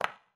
surface_felt5.mp3